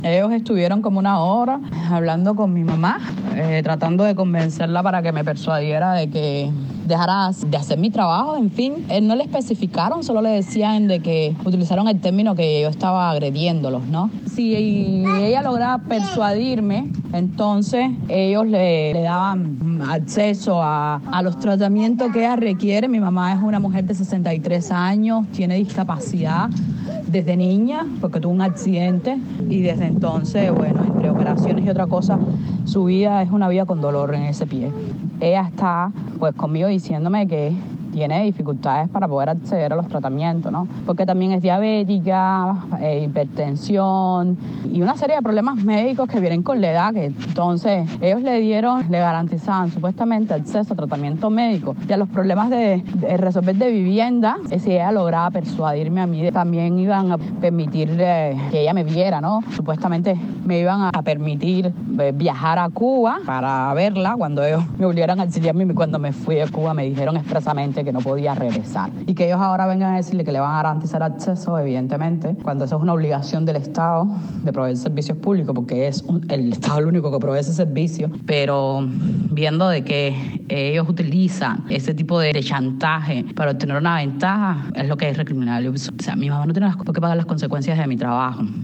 Declaraciones de la abogada